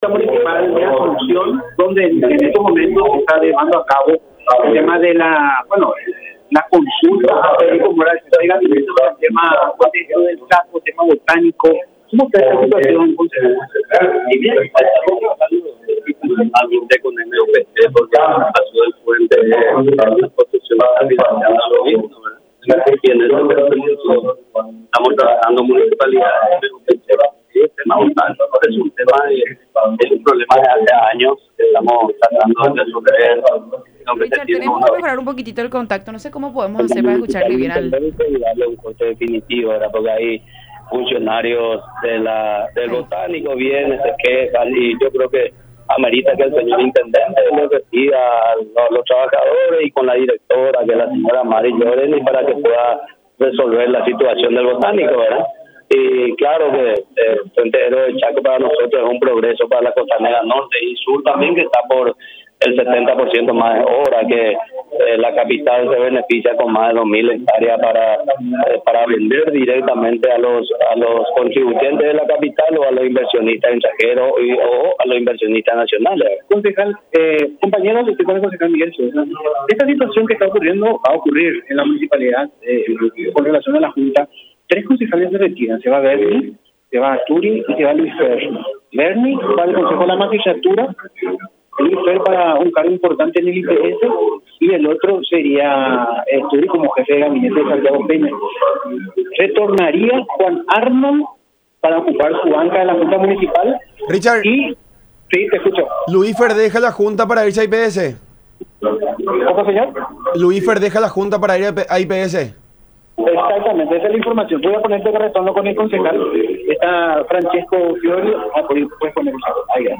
Estas informaciones fueron confirmadas por el concejal asunceno de la ANR Miguel Sosa, en diálogo con La Unión Hace La Fuerza por Unión TV y radio La Unión.